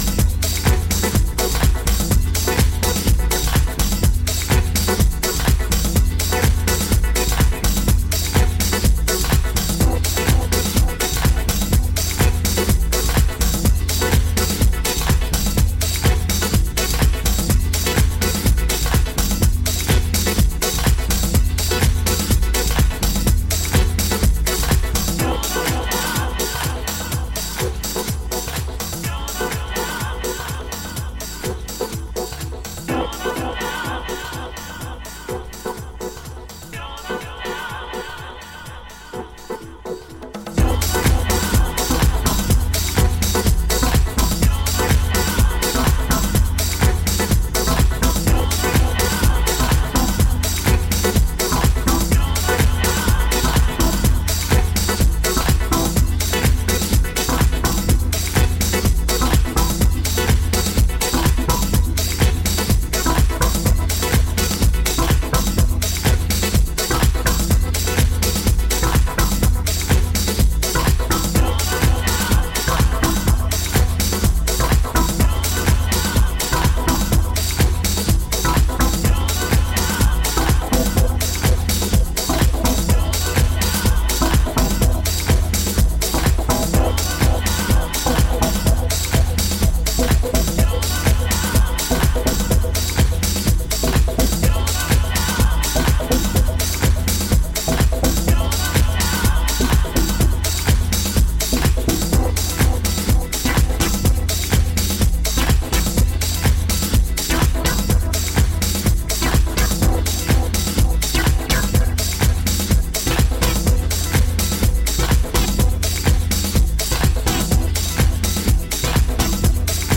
今回はダビーなフィーリングを纏いながらタイトでミニマルなディープ・ハウスを展開。